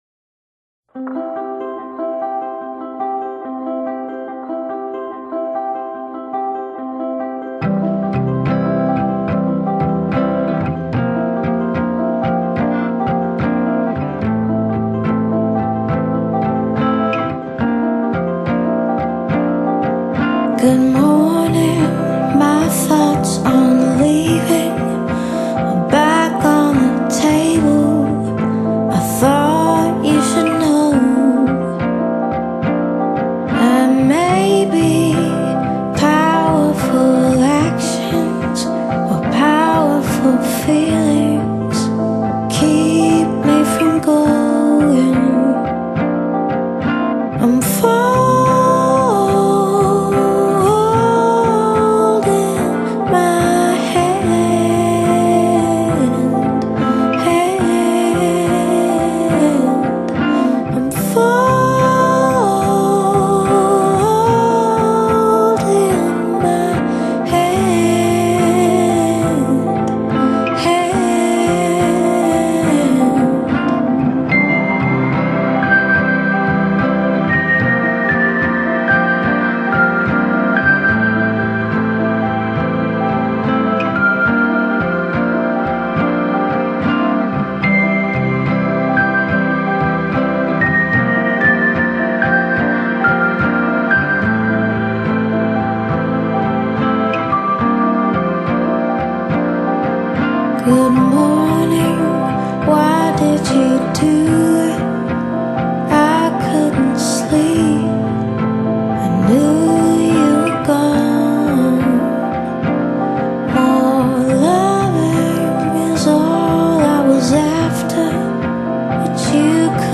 Genre: Pop Vocal, Singer-songwriter